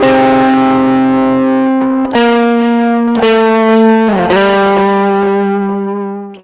Track 15 - Guitar 05.wav